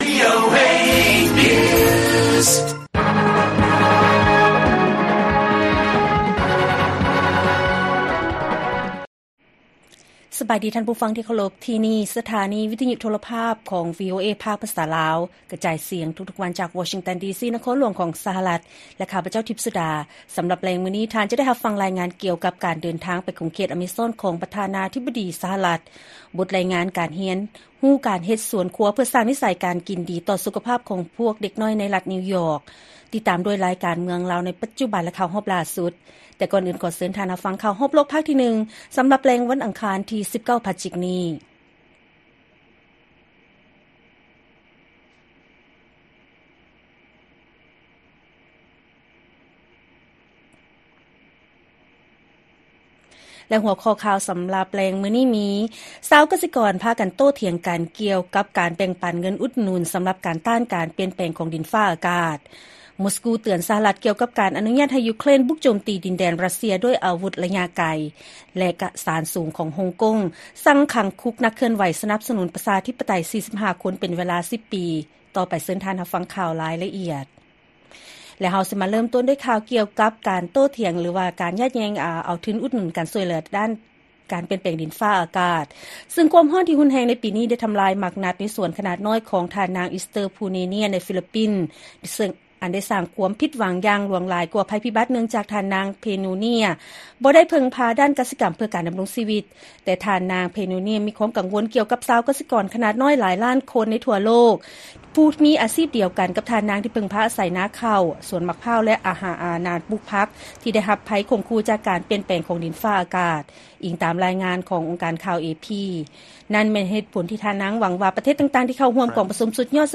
ລາຍການກະຈາຍສຽງຂອງວີໂອເອລາວ: ຊາວກະສິກອນພາກັນໂຕ້ຖຽງກັນ ກ່ຽວກັບການແບ່ງປັນເງິນອຸດໜຸນ ສໍາລັບຕ້ານການປ່ຽນແປງຂອງດິນຟ້າອາກາດ